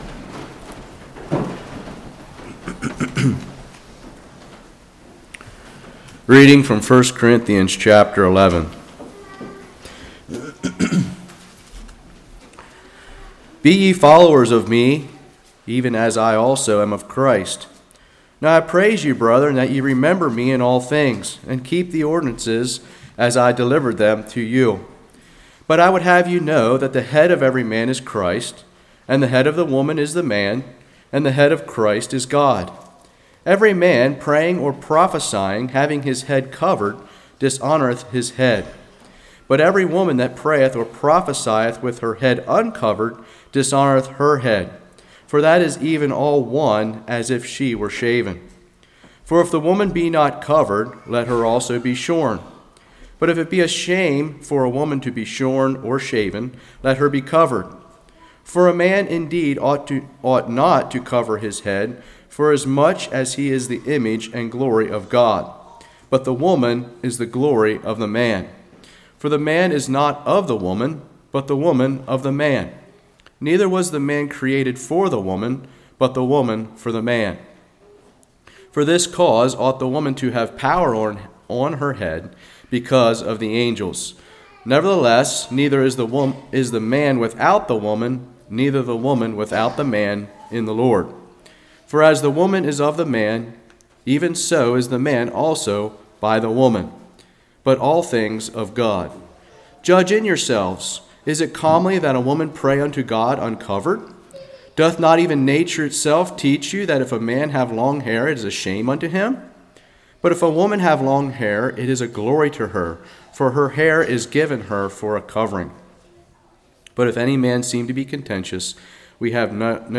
1 Corinthians 11 Service Type: Love Feast Decision we make are run through God’s Word hidden in our heart.